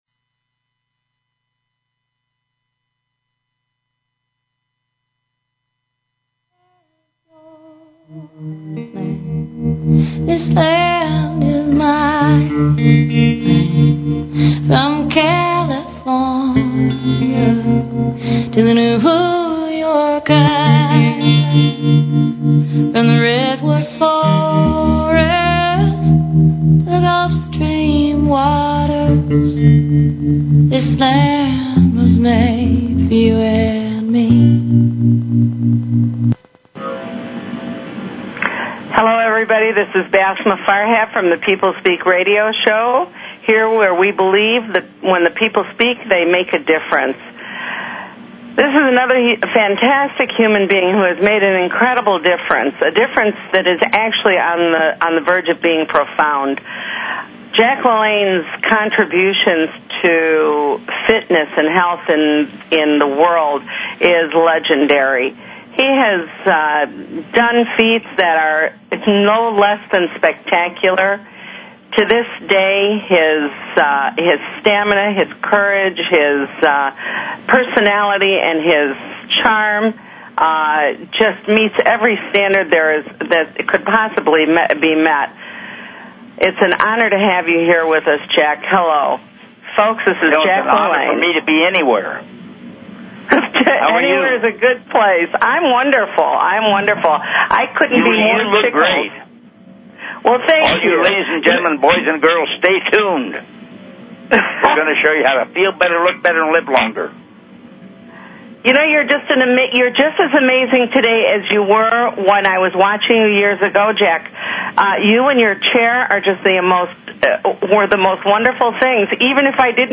Guest, Jack LaLanne